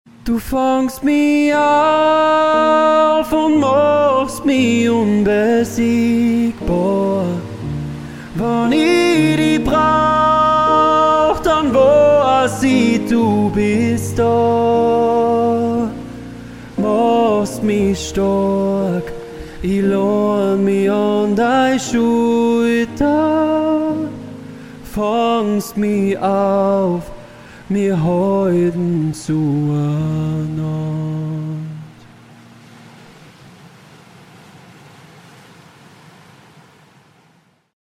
Austro-Pop